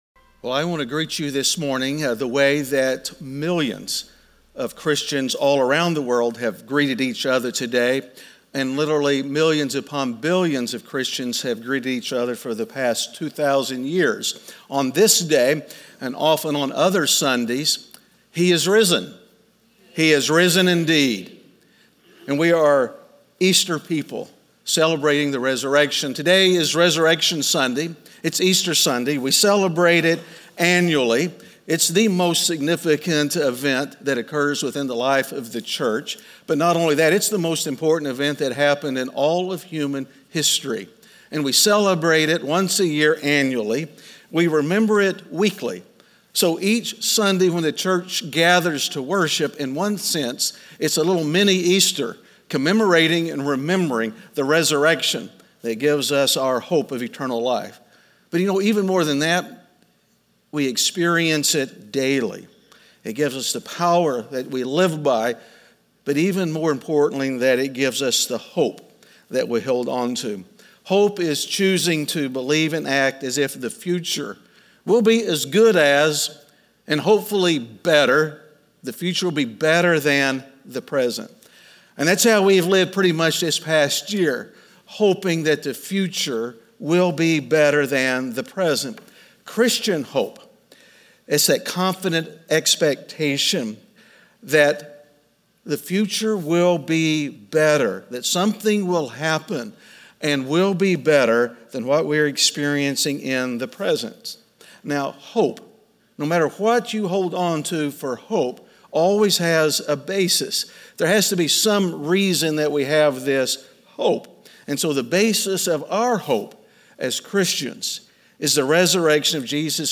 Sermons that aren't a part of a series.
Bethany Easter 2021 - Sermon.mp3